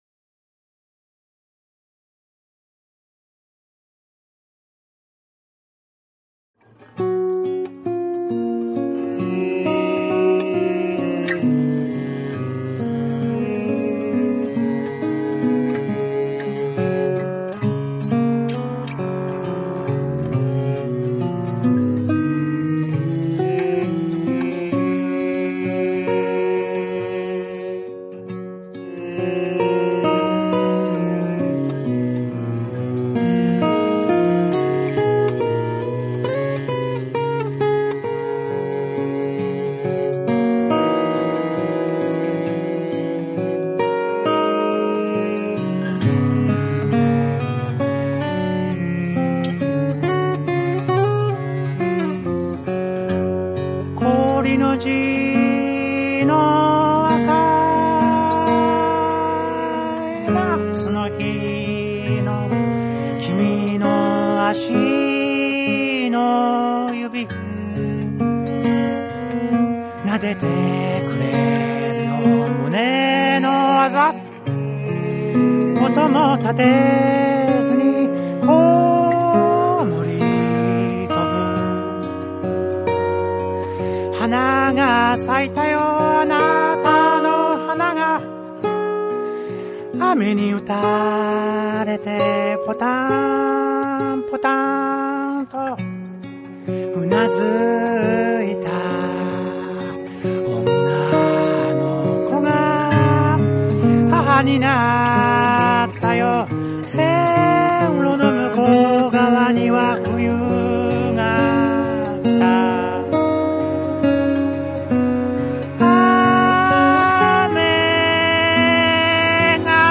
シンプルながらも繊細で力強い音楽が詰まった唯一無二の一枚が誕生した。
JAPANESE / NEW WAVE & ROCK / NEW RELEASE(新譜)